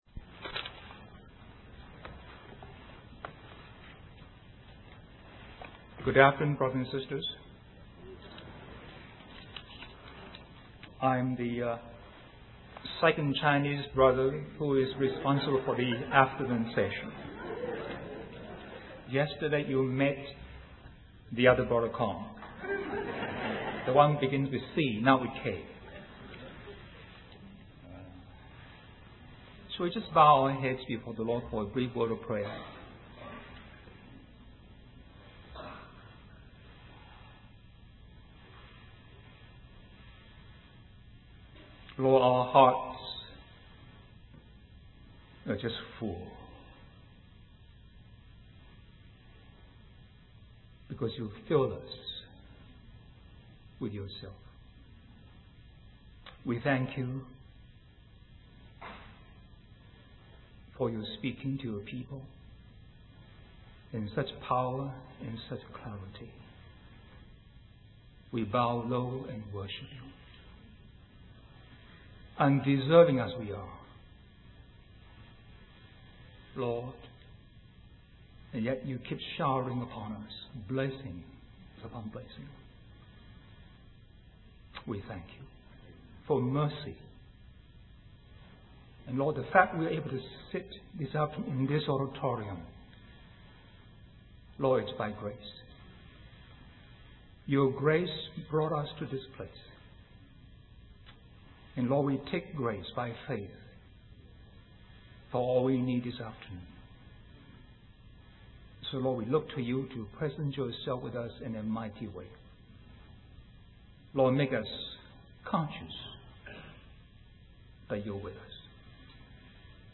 In this sermon, the preacher emphasizes the importance of recognizing Jesus as God's anointed king. He highlights how the world has rejected and crucified Jesus, but believers have the opportunity to enthrone him in their hearts. The preacher also discusses the concept of giving with a purpose, referencing the story of Barzillai giving to King David with the expectation of being honored in return.